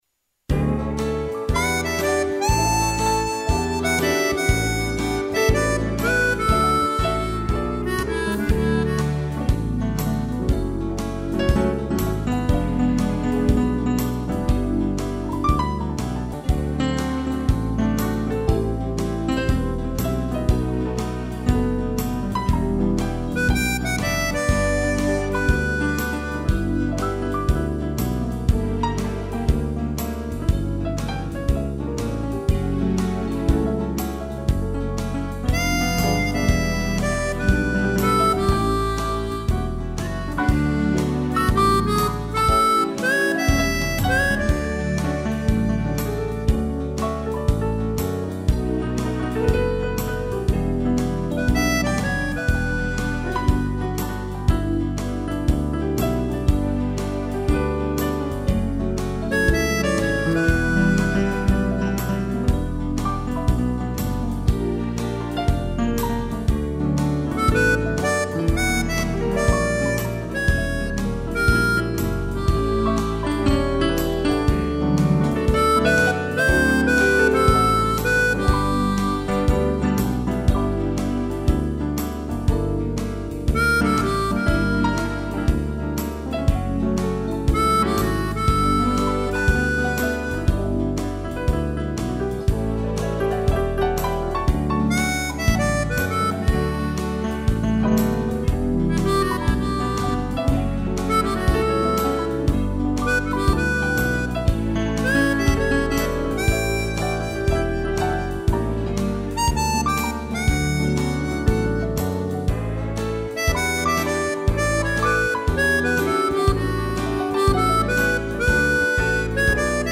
piano e gaita
(instrumental)